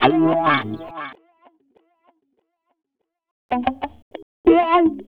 Ridin_ Dubs - Wah Guitar.wav